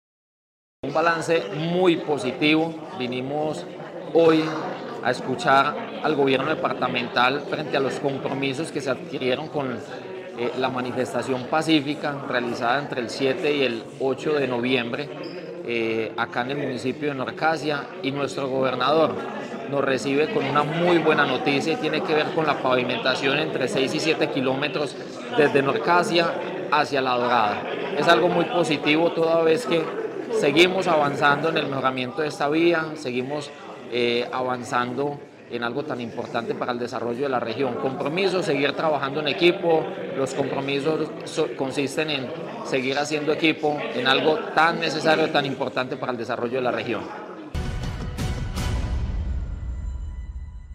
Diego Fernando Olarte Alzate, alcalde de Norcasia